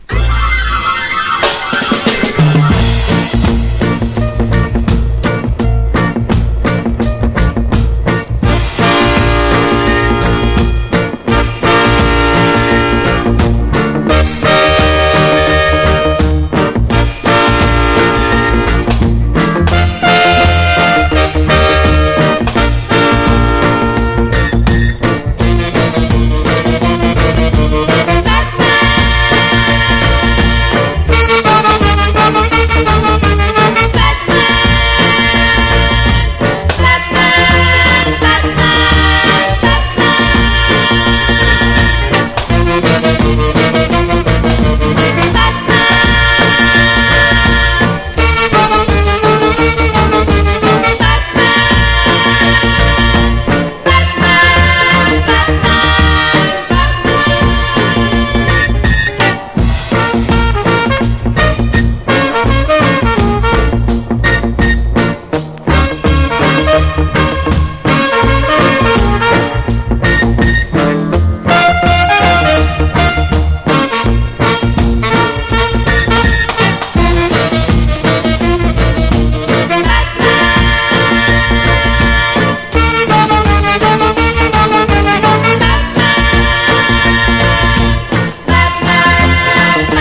B面も同じスタイルのアップテンポでヘヴィ―なロックステディ・インスト。
SKA / ROCK STEADY